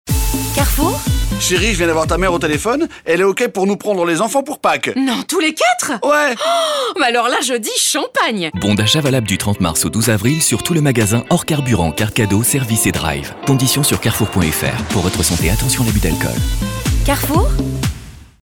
Sa diction irréprochable, sa rigueur technique et sa capacité à garder une intention malgré la contrainte de temps font toute la différence.
4. CARREFOUR chaleureux